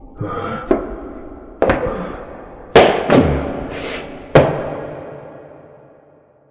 scream_3.wav